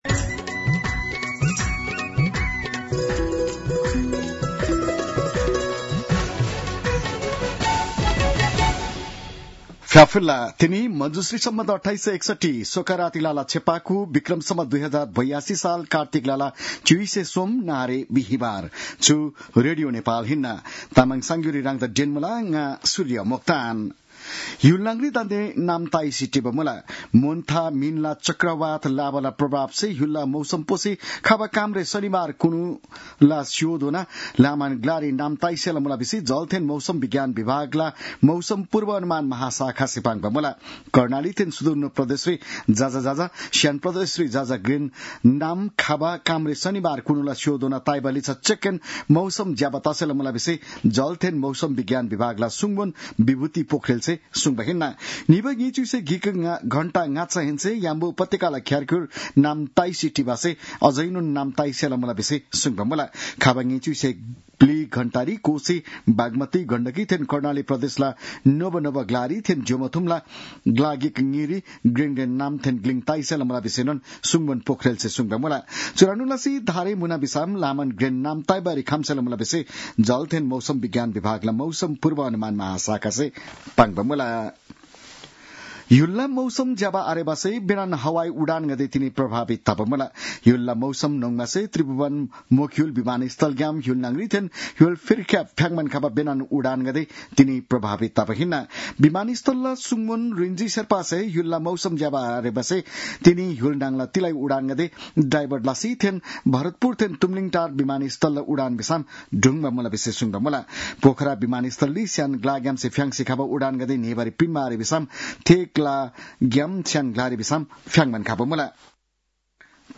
तामाङ भाषाको समाचार : १३ कार्तिक , २०८२